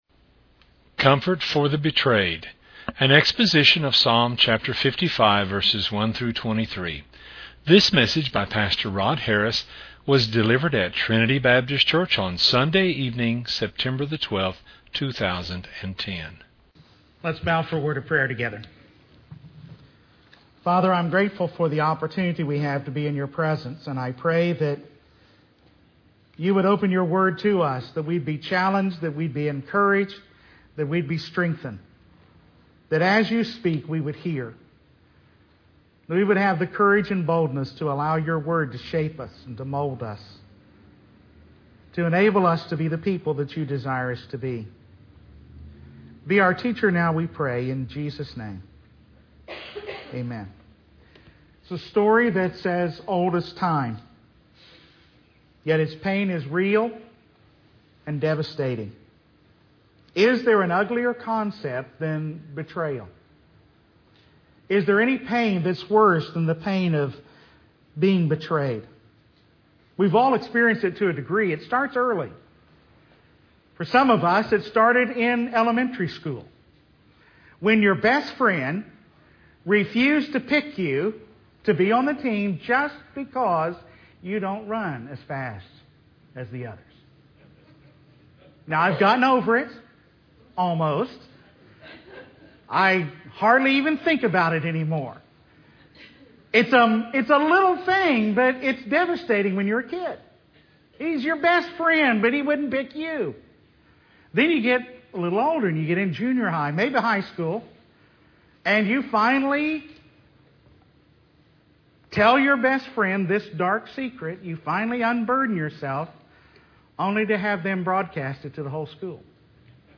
An exposition of Psalm 55:1-23.